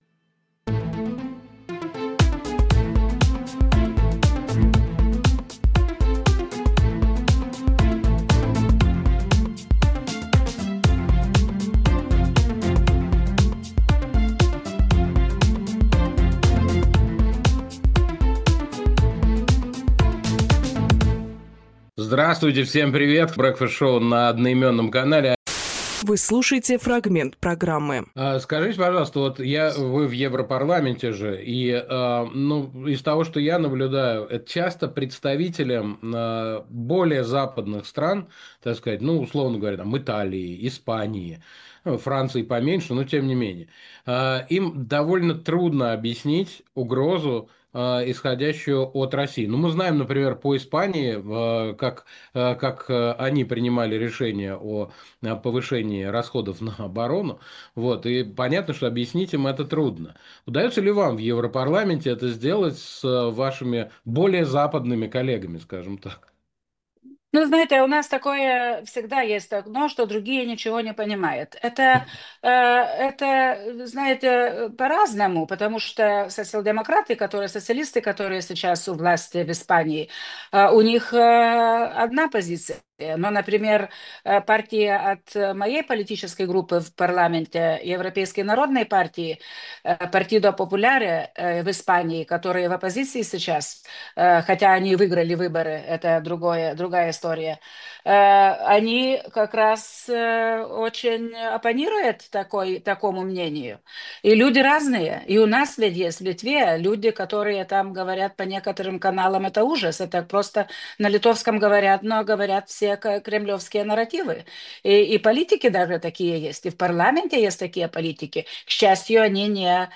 Раса Юкнявиченедепутат Европарламента и экс-министр обороны Литвы
Фрагмент эфира от 11.07.25